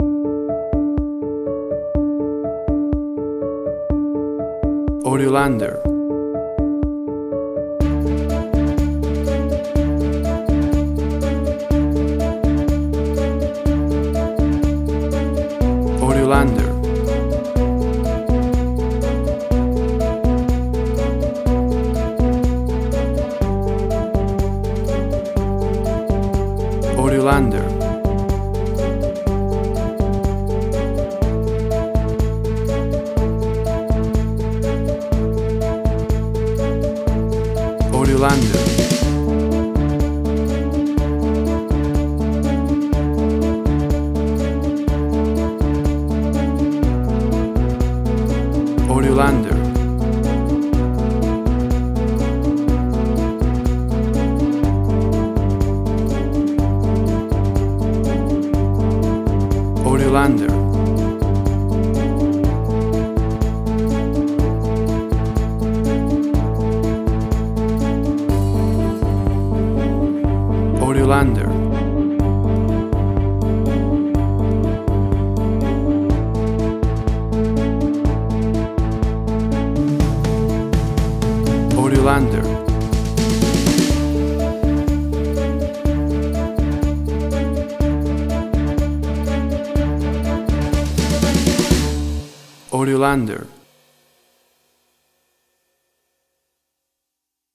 Suspense, Drama, Quirky, Emotional.
Tempo (BPM): 123